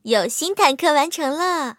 M22蝉建造完成提醒语音.OGG